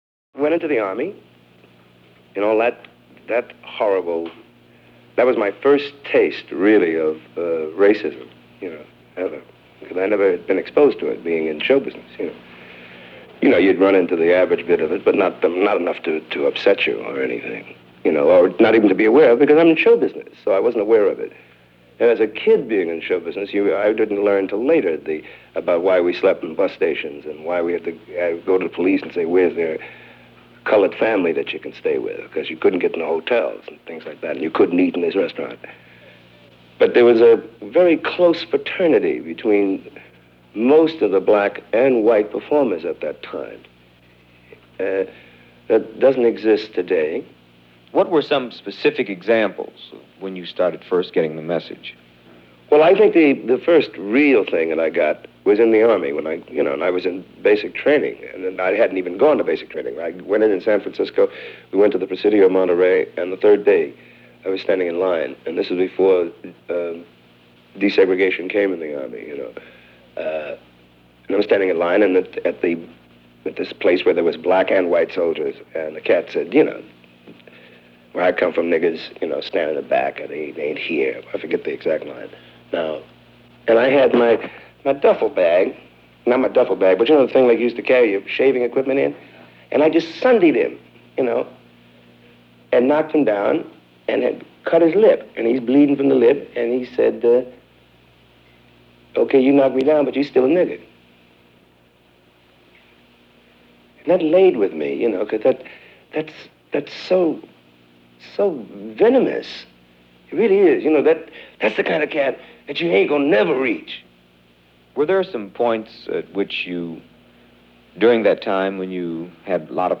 This interview, part of the Black Journal series from NET (precursor to PBS) from 1971 is remarkably candid and frank. He talks about his experiences growing up, starting in show business, his experiences with racism in the Military and his then-current situation regarding his own TV series .
Sammy-Davis-Jr.-Interview-1971.mp3